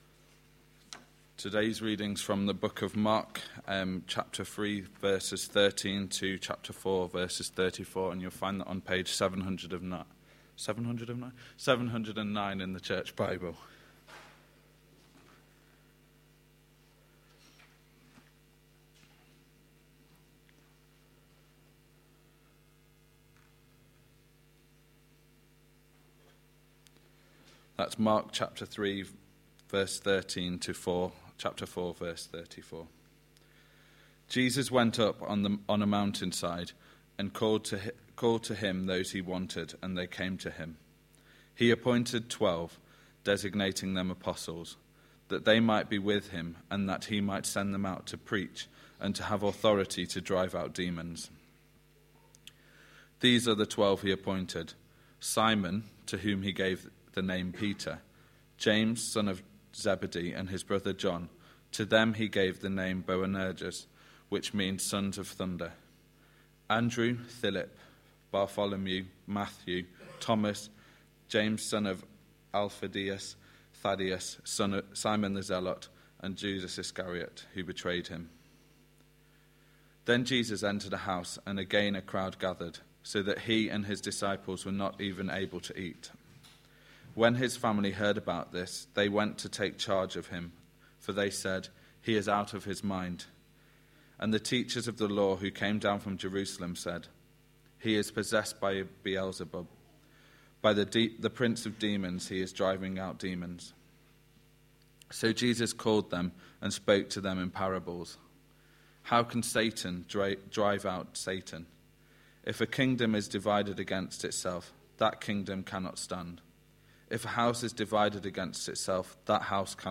A sermon preached on 30th October, 2011, as part of our Mark series.